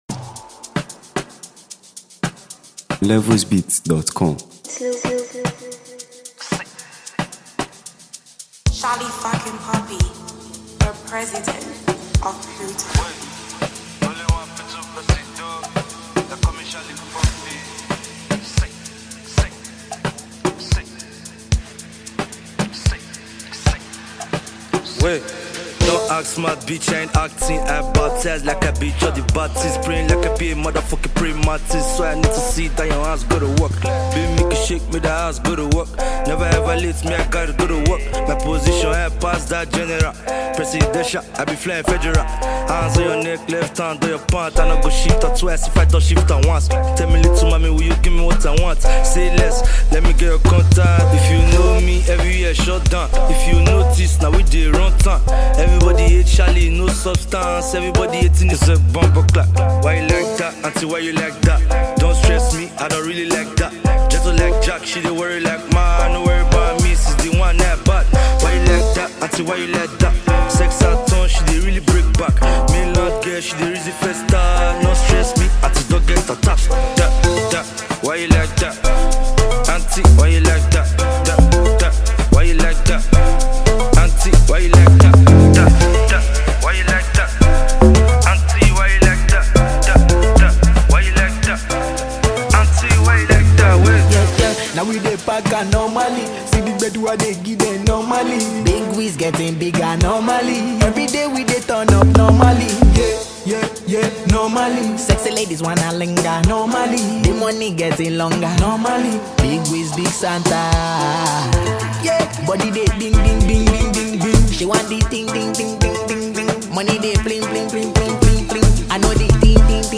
Nigeria Music 2025 3:38